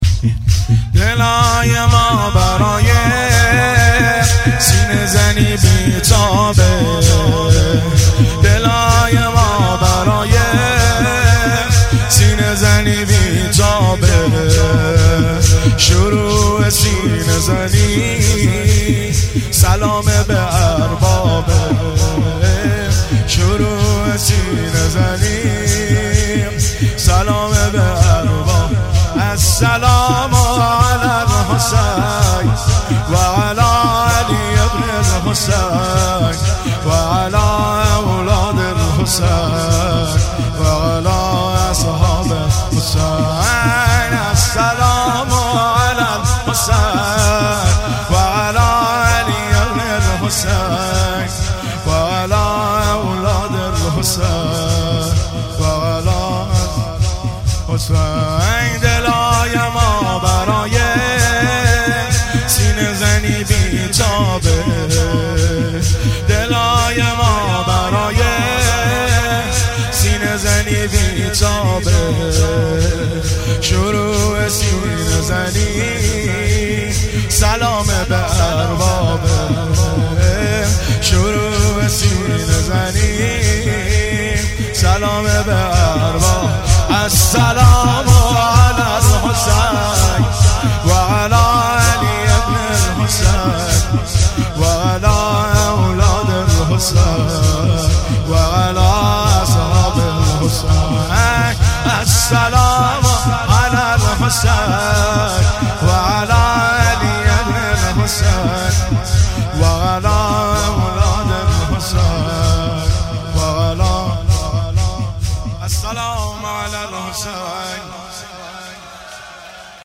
مداحی شب چهارم محرم